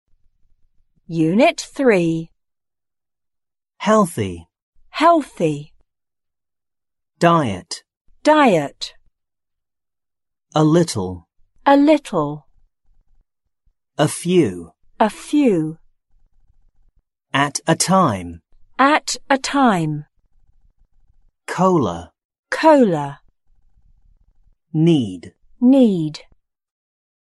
六年级英语下Unit3单词.mp3